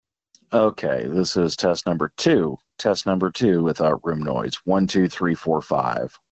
In contrast, G.722 wideband technology significantly expands the audio frequency range to 50 Hz to 7 kHz. This broader spectrum captures more of the human voice’s natural tones, delivering a much fuller and more nuanced sound.
HDcalling.mp3